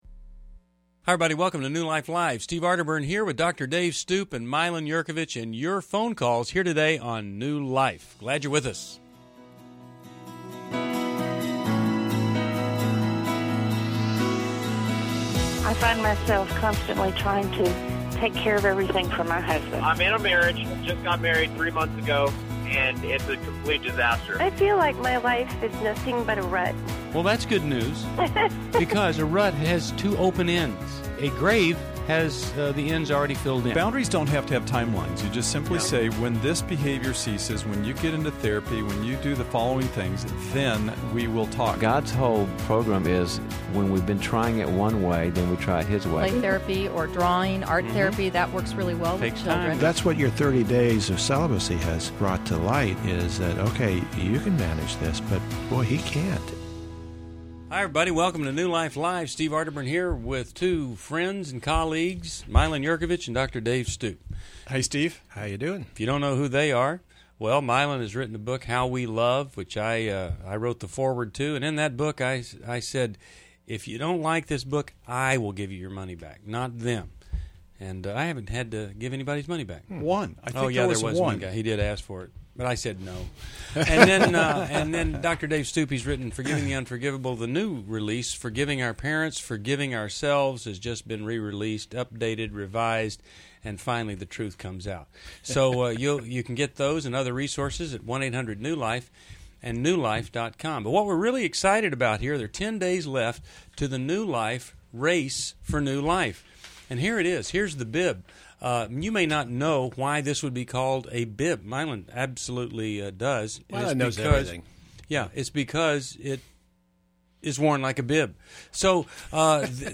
Caller Questions: 1. How can I deal with my wife’s affair?